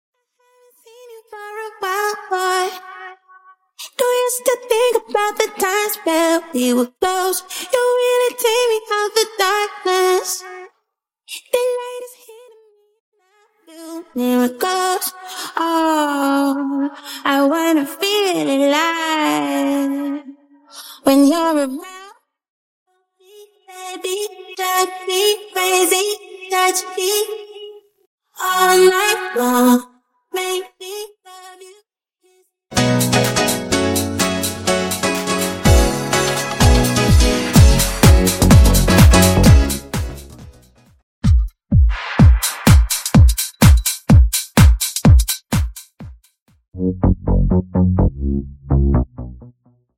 Bassline Stem
Instrumental
Percussion & Drums Stem